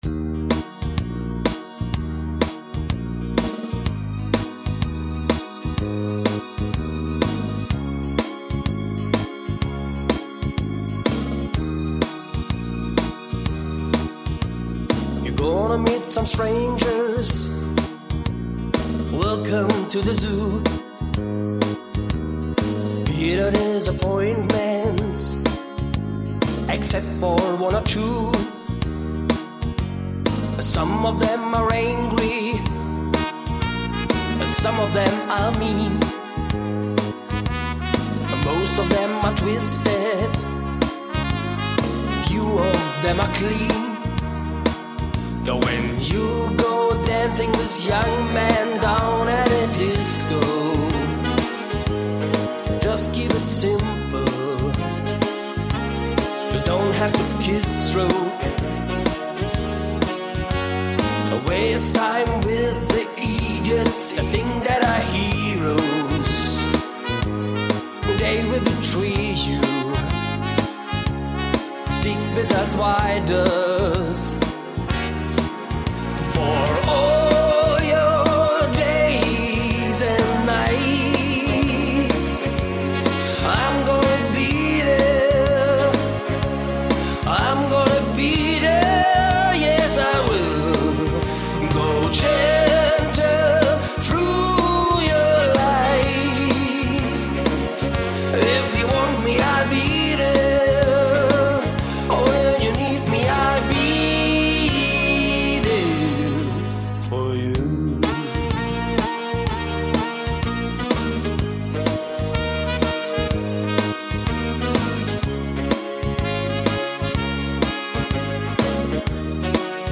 Partyband Hochzeit Fasnet Singen Hegau Bodensee
• Allround Partyband